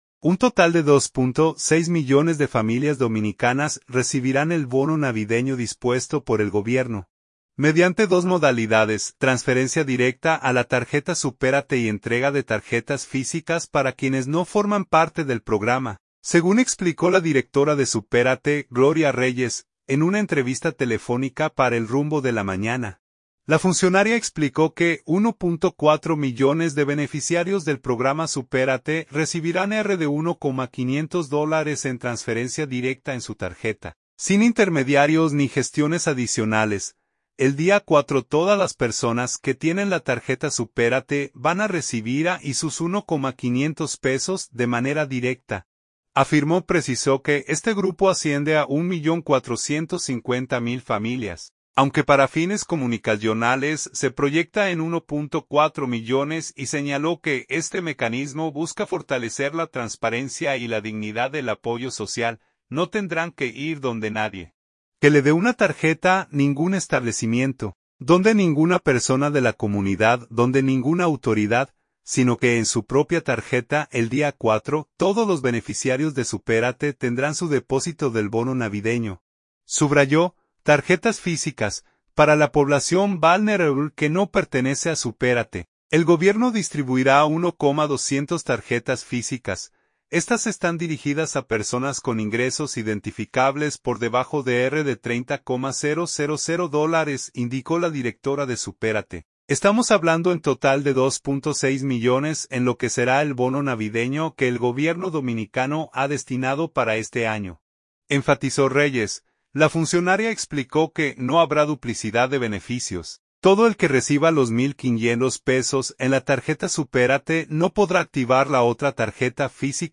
En una entrevista telefónica para El Rumbo de la Mañana, la funcionaria explicó que 1.4 millones de beneficiarios del programa Supérate recibirán RD$1,500 en transferencia directa en su tarjeta, sin intermediarios ni gestiones adicionales.